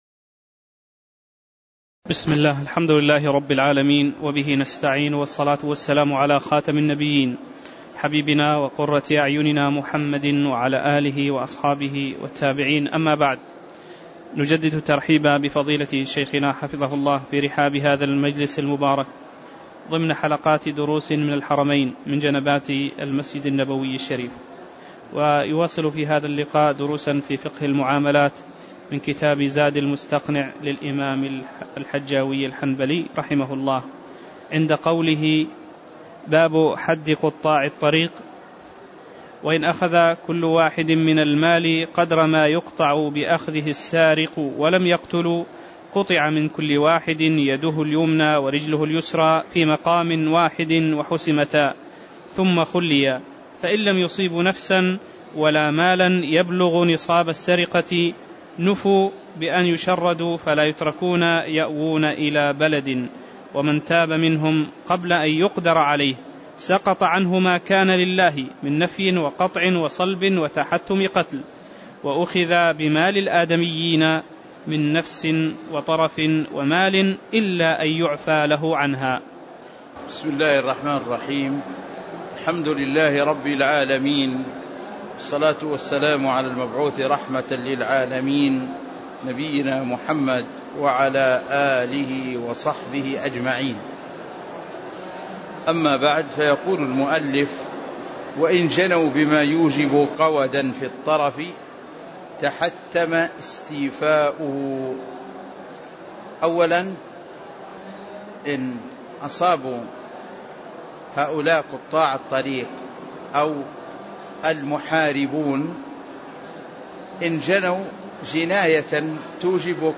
تاريخ النشر ٢٩ جمادى الأولى ١٤٣٨ هـ المكان: المسجد النبوي الشيخ